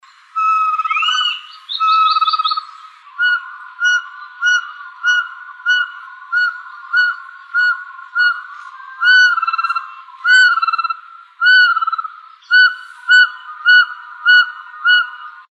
Caburé Chico (Glaucidium brasilianum)
Nombre en inglés: Ferruginous Pygmy Owl
Localidad o área protegida: Reserva Ecológica Costanera Sur (RECS)
Condición: Silvestre
Certeza: Vocalización Grabada